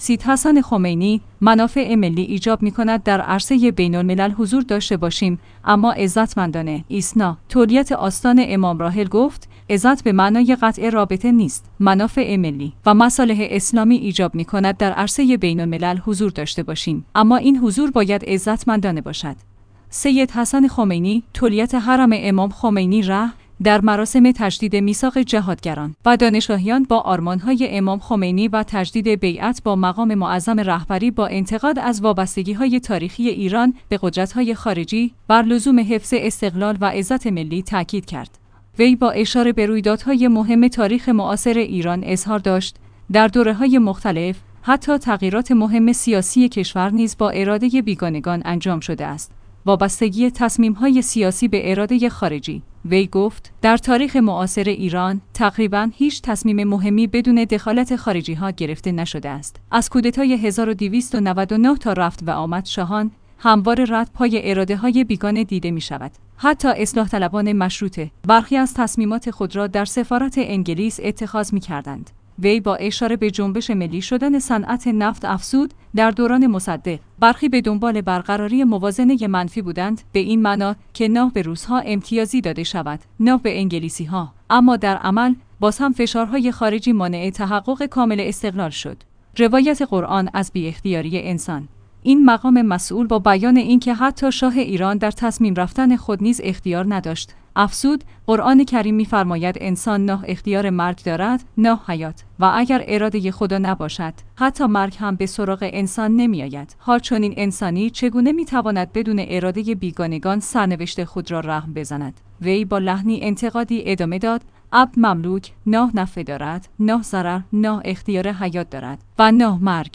منافع ملی و مصالح اسلامی ایجاب می‌کند در عرصه بین‌الملل حضور داشته باشیم؛ اما این حضور باید عزتمندانه باشد. سید حسن خمینی، تولیت حرم امام خمینی(ره)، در مراسم تجدید میثاق جهادگران و دانشگاهیان با آرمان‌های امام خمینی و تجدید بیعت با مقام